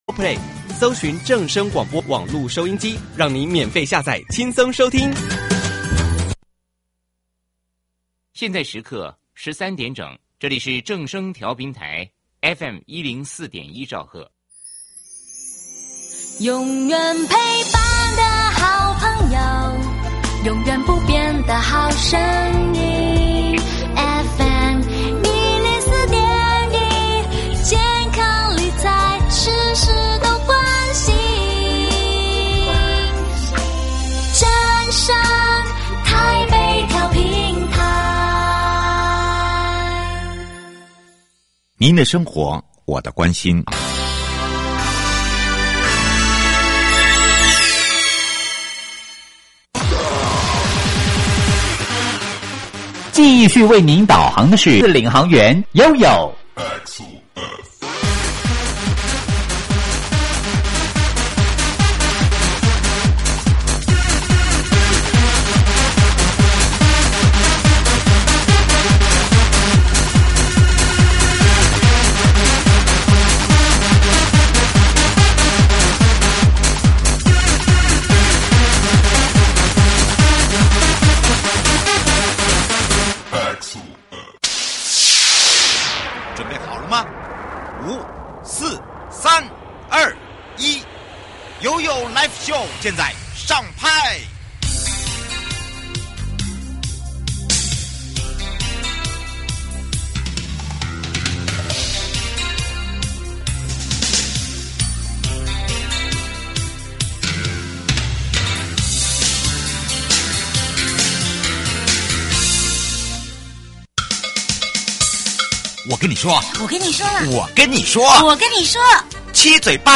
受訪者： 營建你我他 快樂平安行~七嘴八舌講清楚~樂活街道自在同行! 主題：「樂活街道自在同行」嘉義市盧厝里如何活化社區？